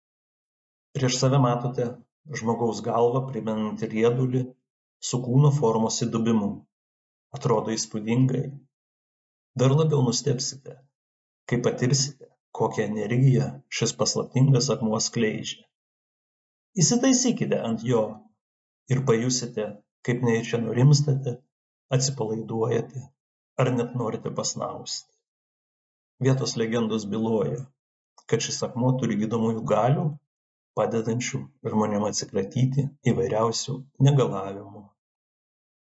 Jūs klausote miškininko pasakojimo